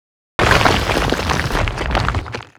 地藏碎裂.wav